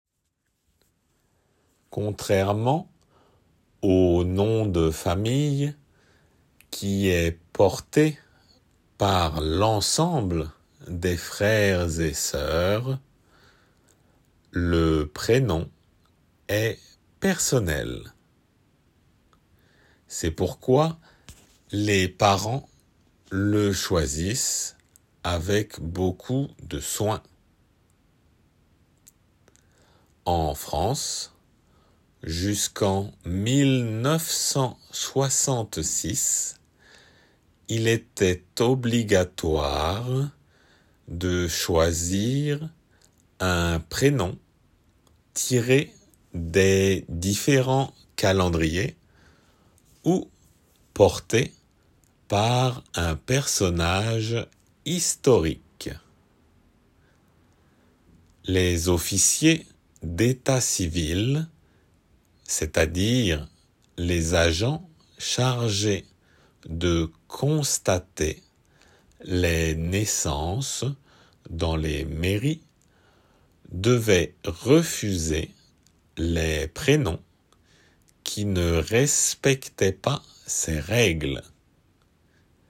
仏検2級―聞き取り―音声－フランス人と名前